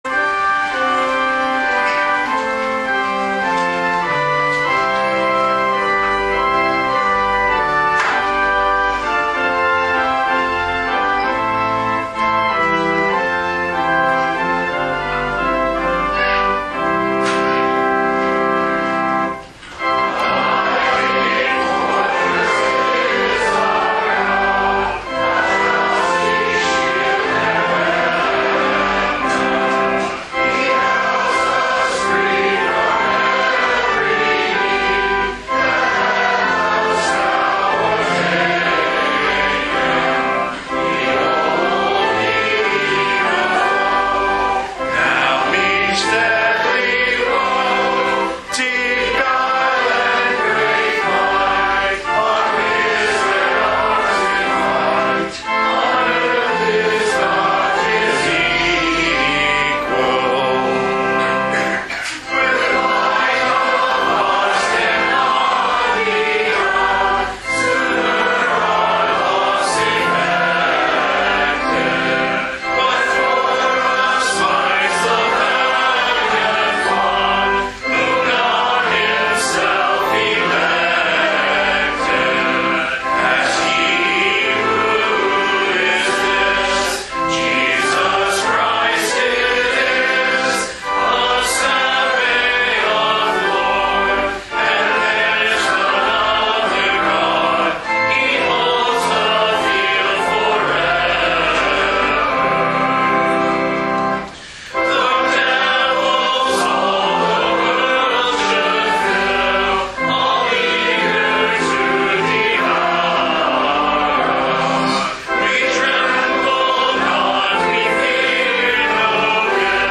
This weeks Sermon Audio